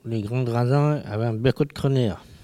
Sallertaine
collecte de locutions vernaculaires
Catégorie Locution